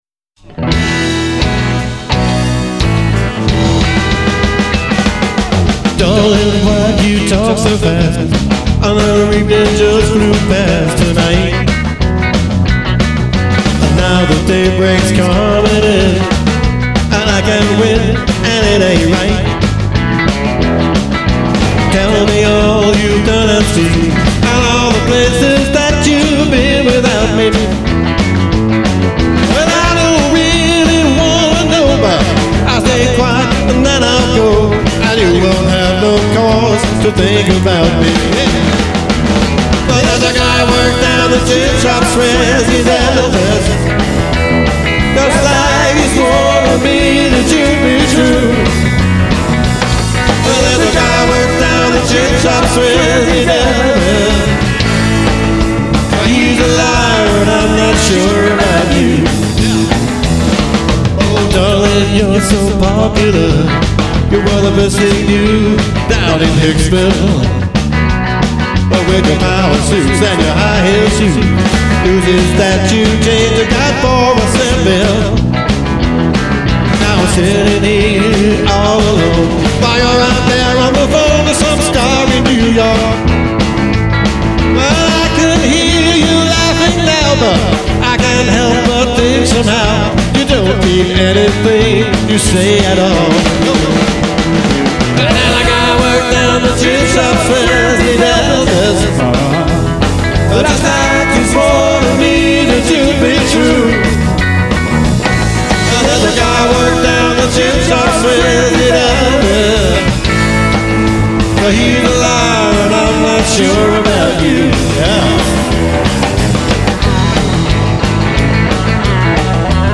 Music from the Golden Age of Vinyl